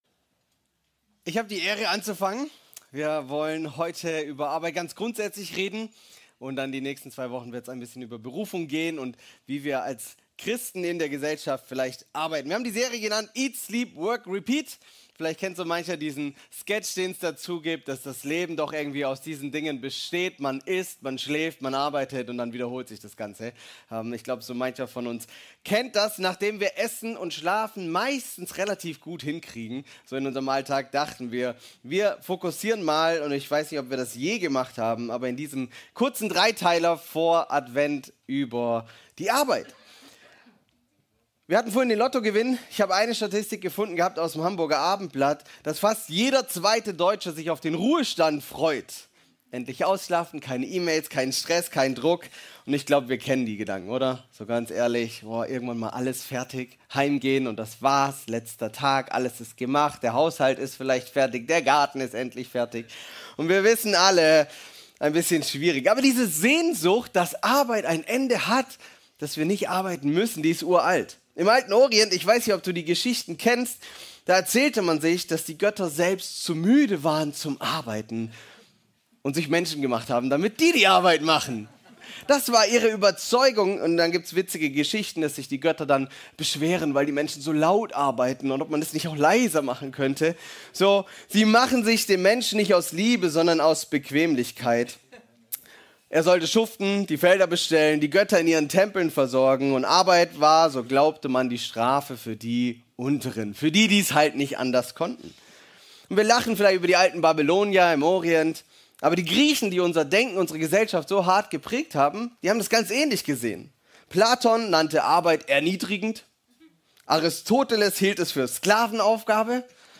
Predigten | Panorama Kirche Göppingen :: verändert | gemeinsam | für Andere